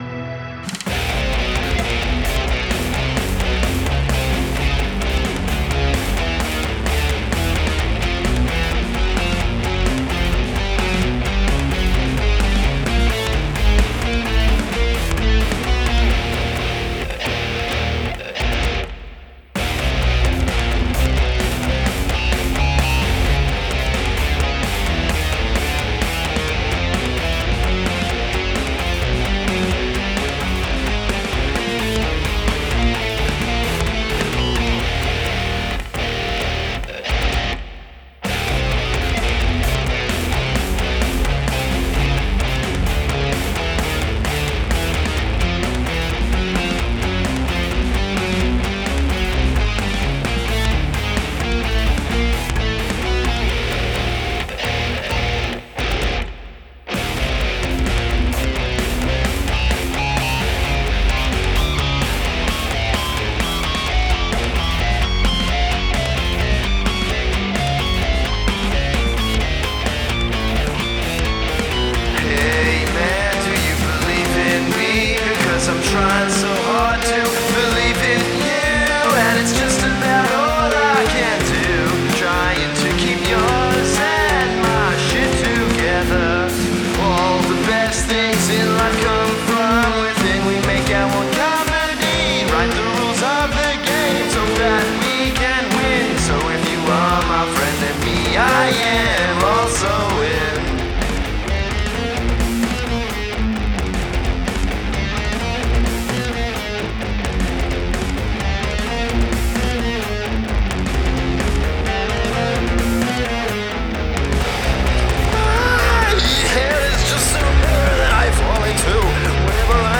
Electronic, Jazz (2023)